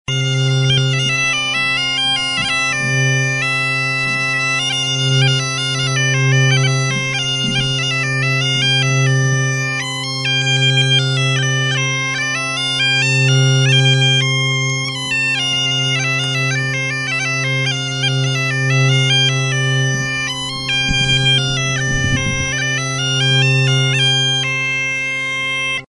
Aerophones -> Reeds -> Bagpipes
CONTRADANSA. HM Udako Kontzertua.
SAC DE GEMECS